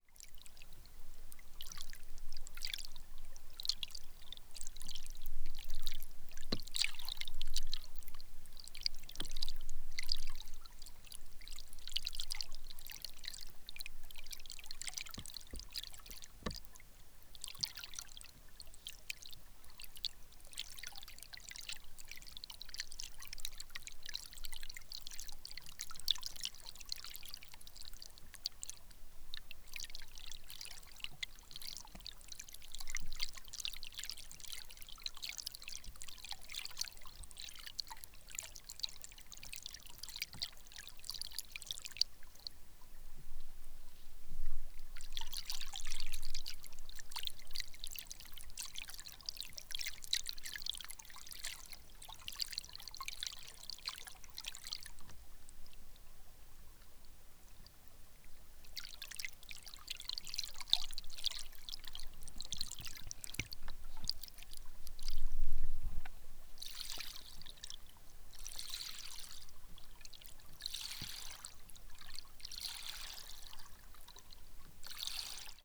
CSC-01-102-GV - Hidrofone em fio dagua bem suave.wav